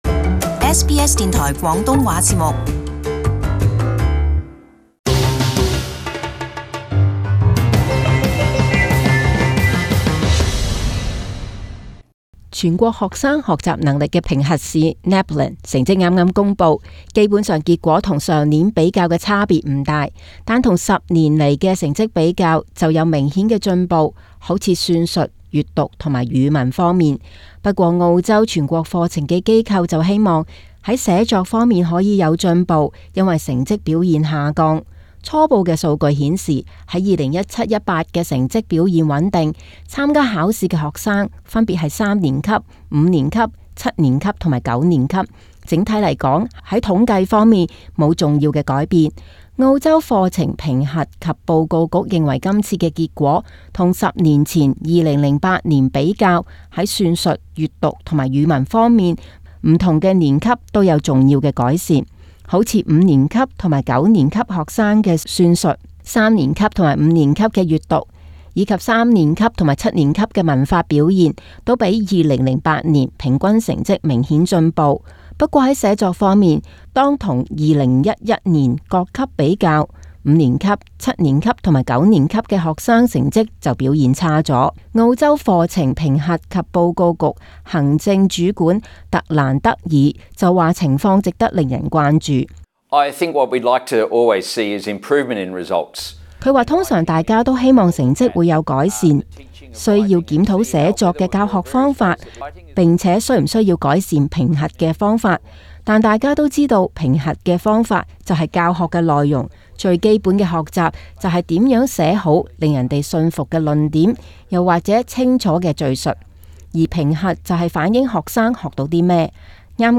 【时事报导】NAPLAN成绩较十年前佳